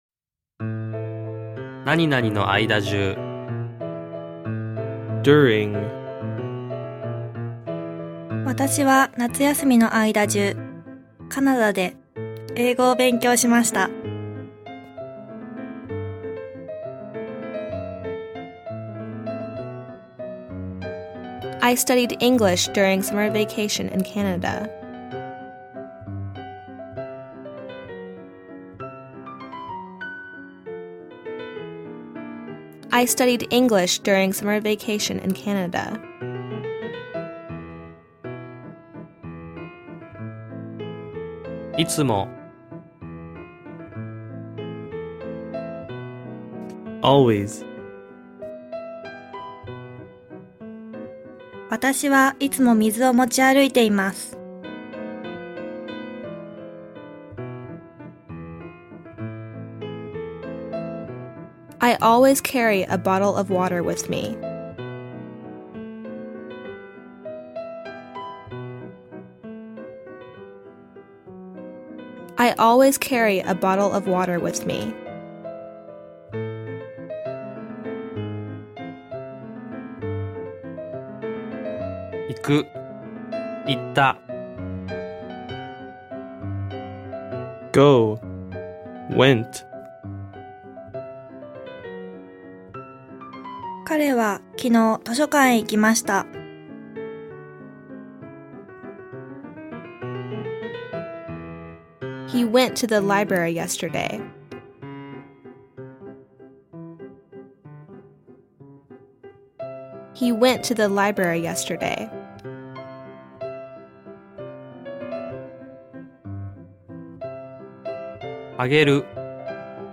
生徒たちが何度聞いても飽きないようにBGMが入れてあります。
BGM付きの「日本語→英語」録音で編集しています。
※MP3音源は「日本語→ネイティブ英語」の順に流れます、BGMを聞きながら